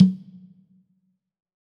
CONGA 38.wav